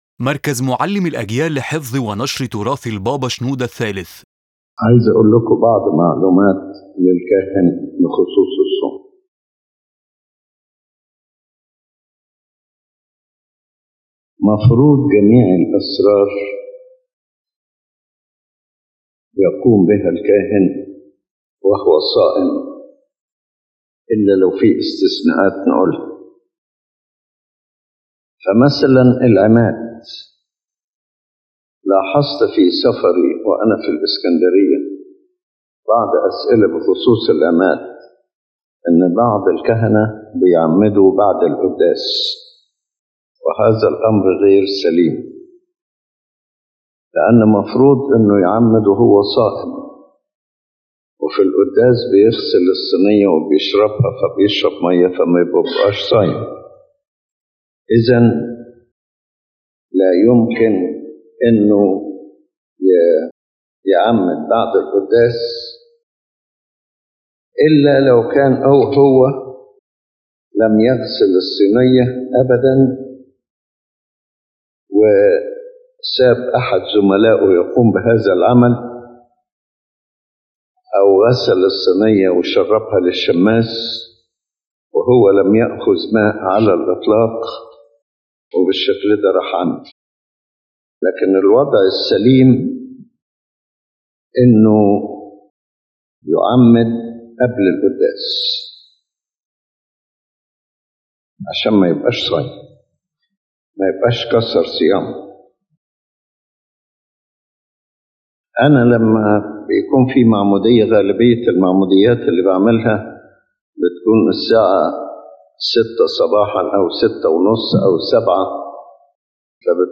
His Holiness Pope Shenouda III states that fasting for the priest is not superficial but a rite to be preserved when administering the sacraments, since most sacraments should be performed by the priest while he is fasting, with some exceptions for necessity.